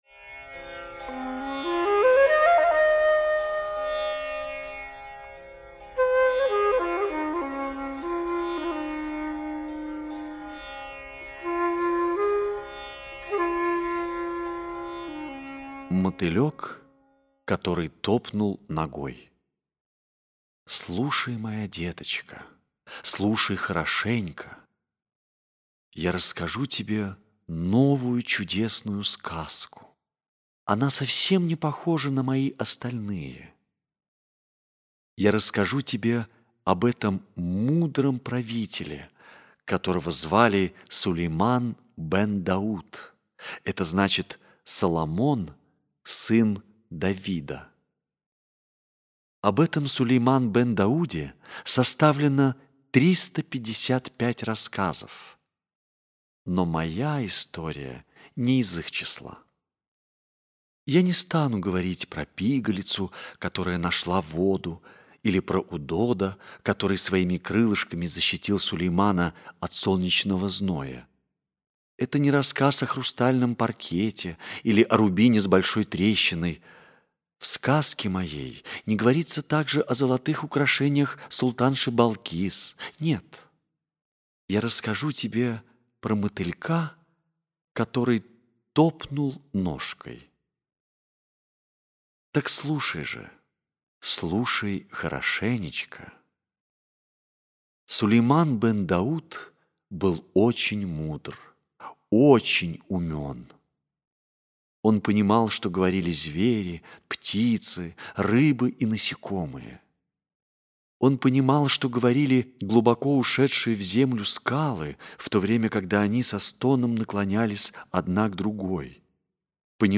Мотылёк, который топнул ногой - аудиосказка Киплинга - слушать